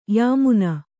♪ yāmuna